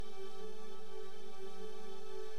• Background Texture Modulated synth 100 BPM.wav
Background_Texture_Modulated_synth_100_BPM_-1_TXO.wav